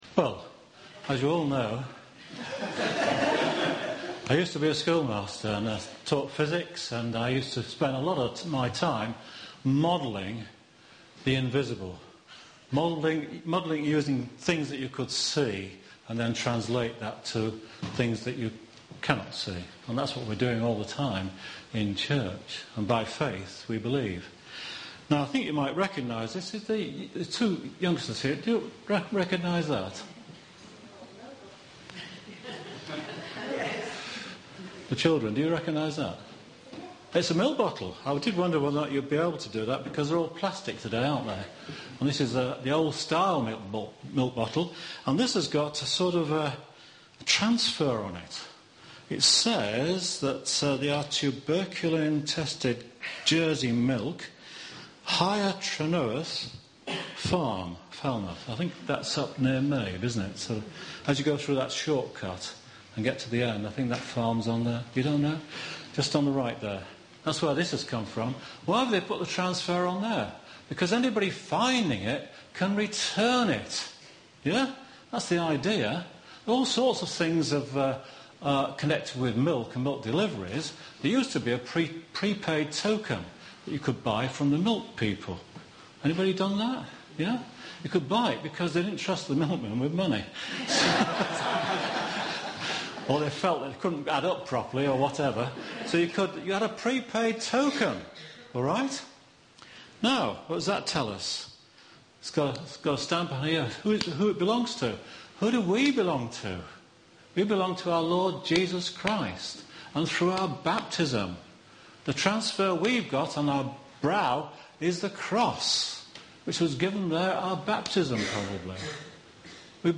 Family Service